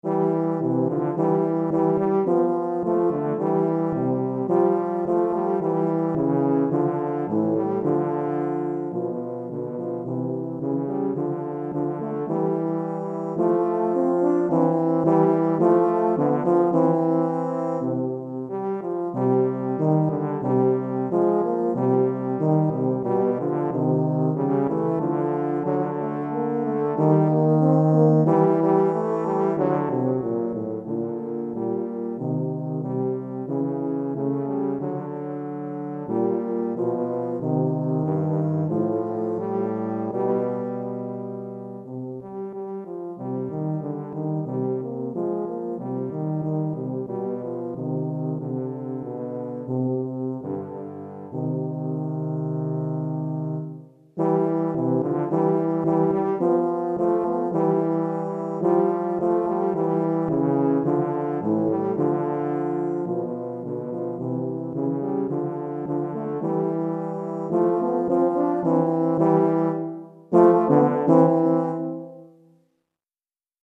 Pour 2 saxhorns ou euphoniums DEGRE fin de cycle 1 Durée